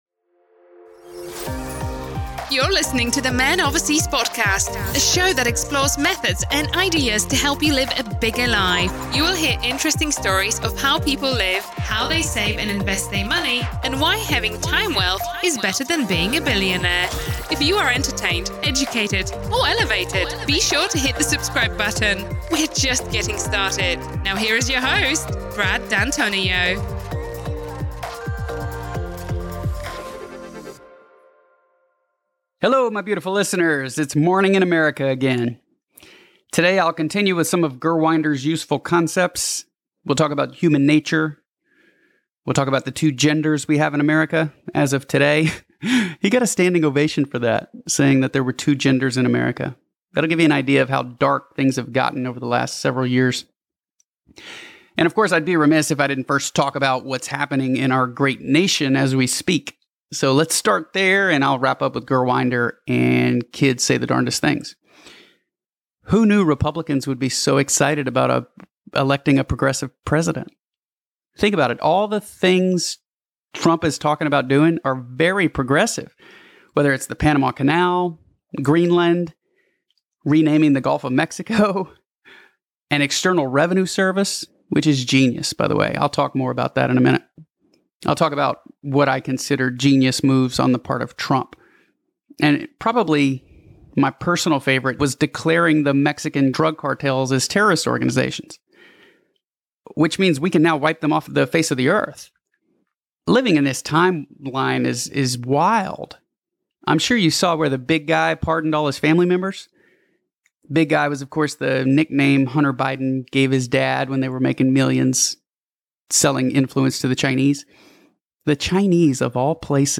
solo-episode-12-full.mp3